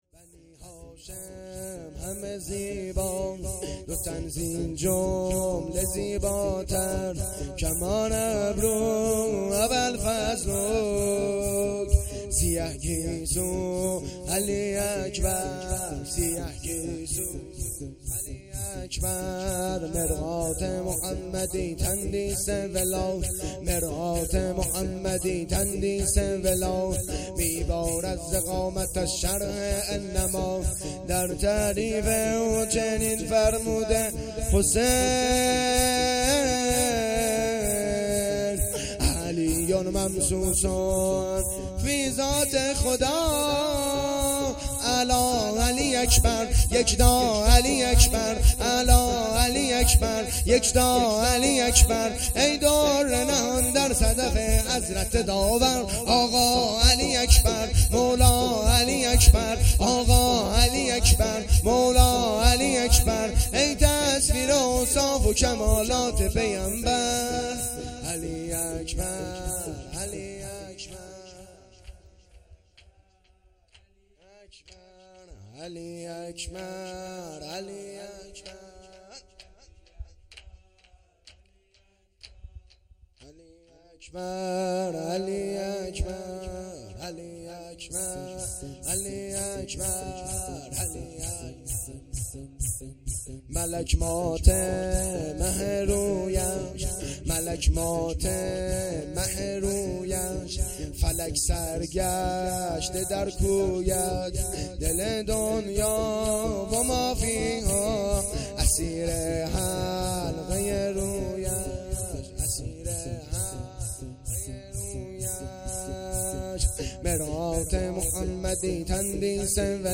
شور
شام شهادت امام حسن مجتبی ع(۷صفر)- سال ۱۴۰۰